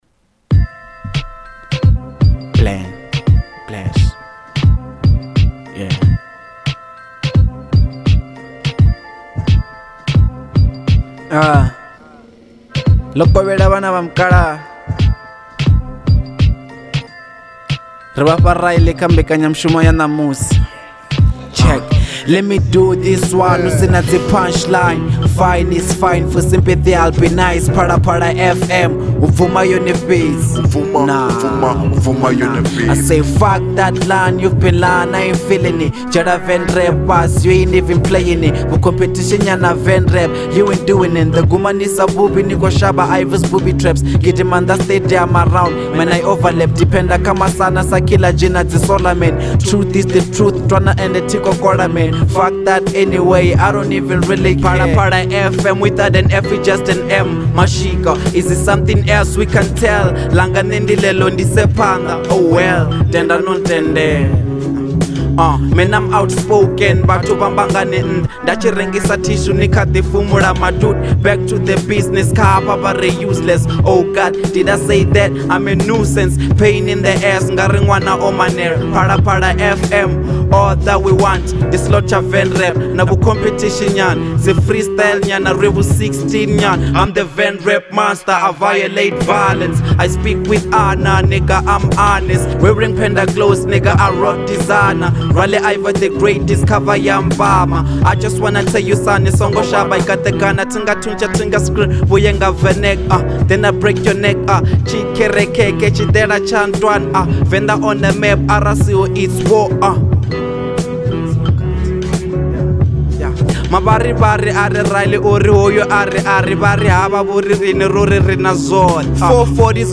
02:36 Genre : Venrap Size